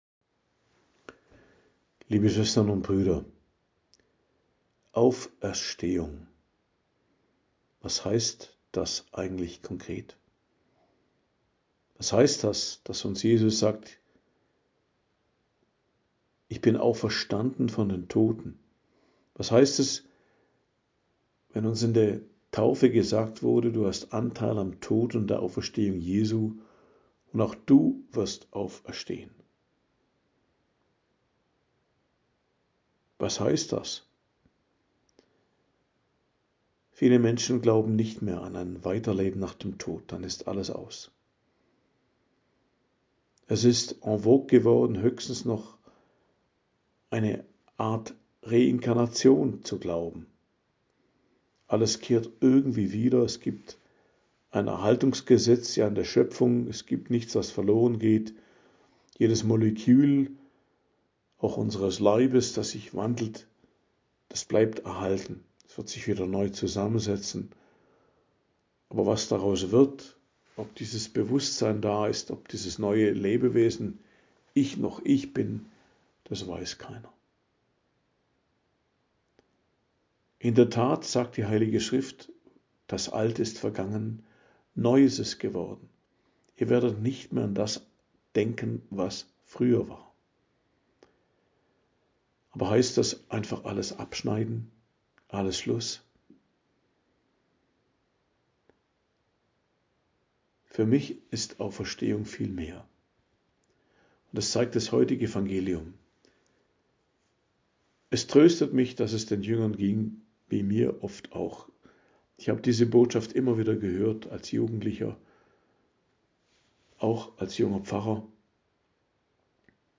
Predigt am Donnerstag der Osteroktav, 24.04.2025